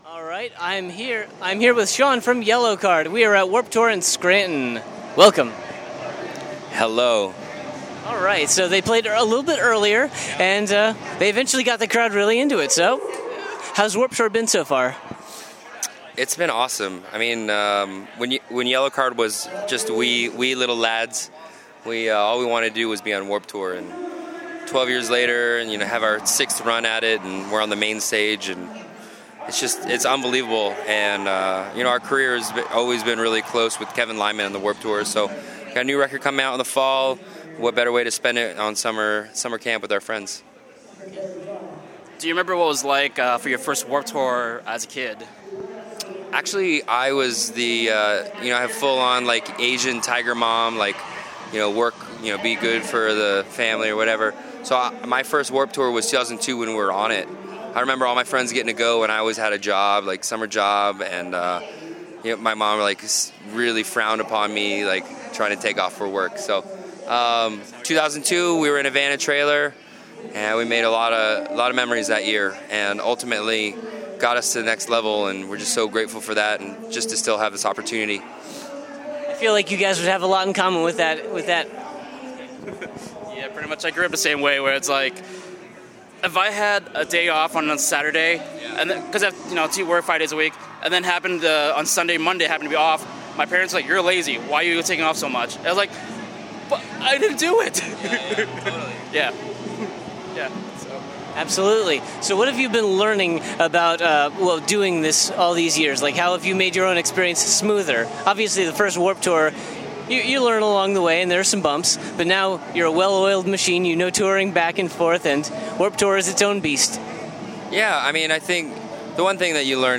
When the opportunity arose for me to speak to violinist Sean Mackin , I was certainly willing, but ill-prepared.
50-interview-yellowcard.mp3